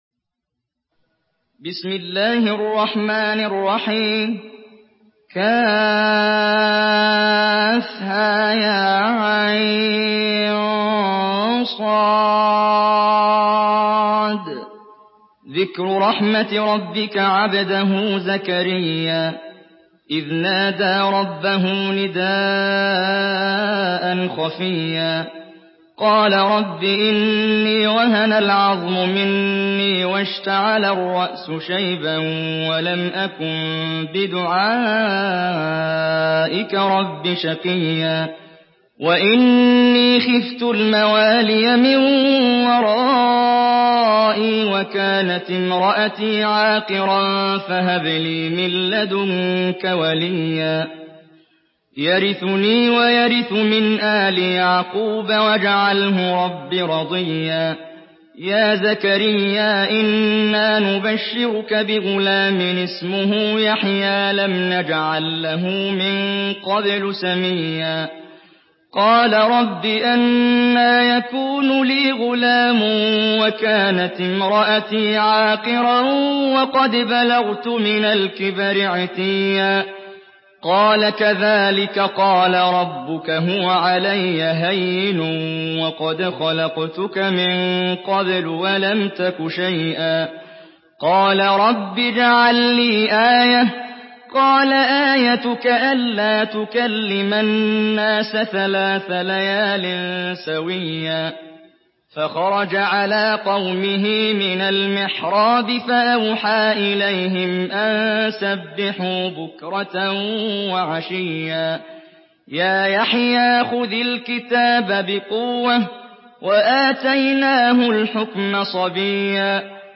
Surah مريم MP3 by محمد جبريل in حفص عن عاصم narration.
مرتل حفص عن عاصم